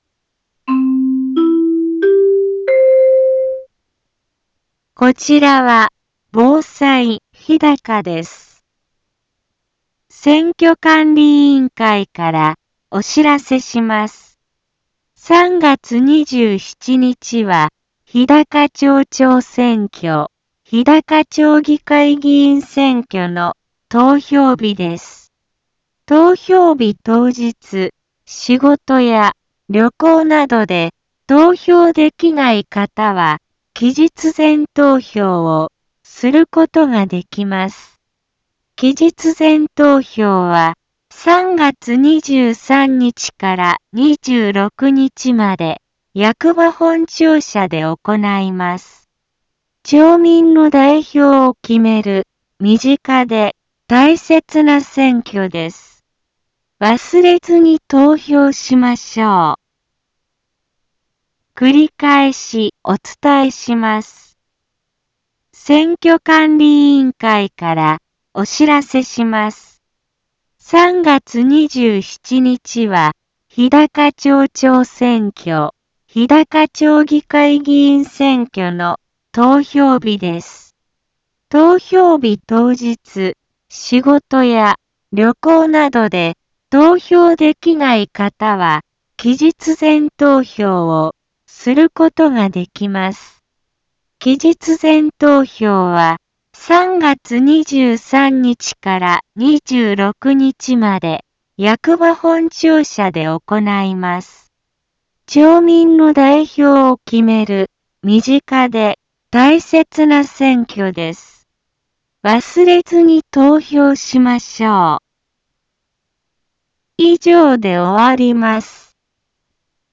一般放送情報
Back Home 一般放送情報 音声放送 再生 一般放送情報 登録日時：2022-03-18 15:04:33 タイトル：日高町長選挙及び日高町議会議員選挙投票棄権防止のお知らせ インフォメーション：こちらは防災日高です。